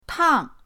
tang4.mp3